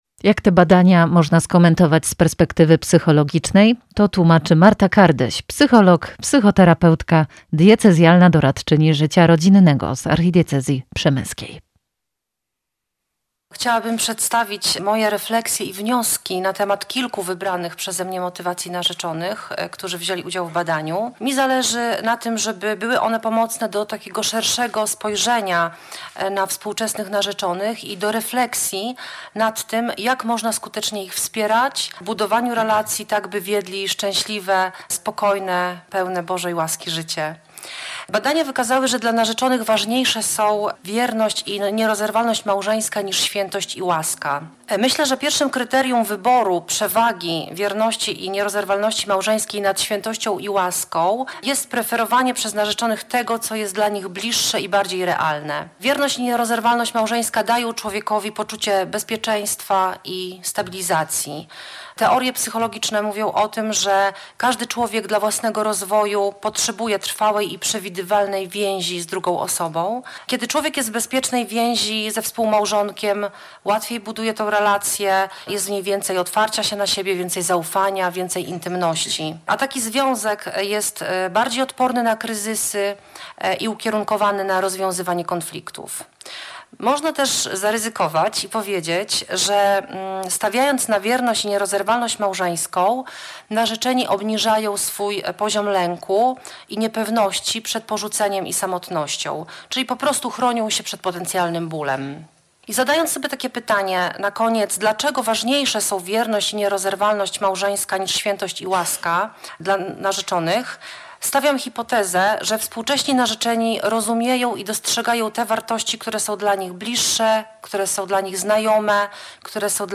W konferencji wzięli udział: abp Wiesław Śmigiel, metropolita szczecińsko-kamieński, przewodniczący Rady Konferencji Episkopatu Polski ds. Rodziny